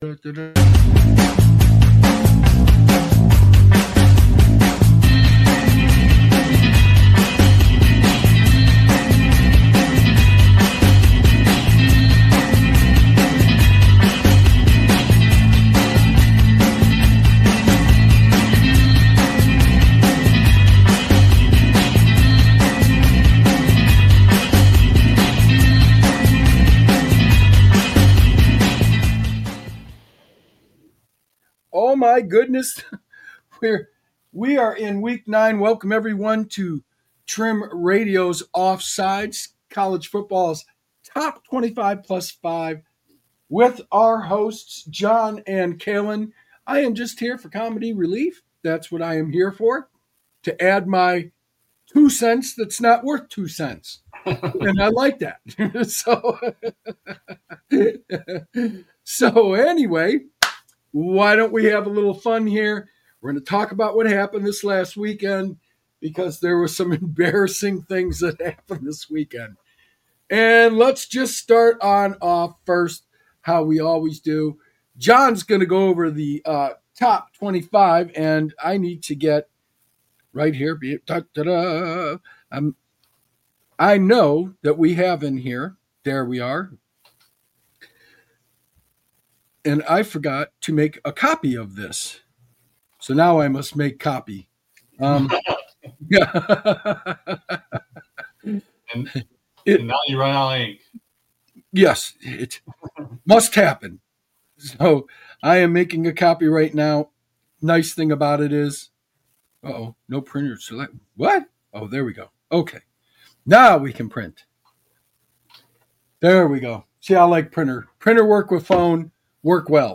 And I'm just here for comedy relief, adding my two cents that's not worth two cents, but I like it! This week, we're diving deep into the embarrassing happenings of last weekend, starting with the shocking wave of coaching firings.